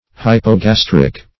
Search Result for " hypogastric" : The Collaborative International Dictionary of English v.0.48: Hypogastric \Hyp`o*gas"tric\, a. [Cf. F. hypogastrique.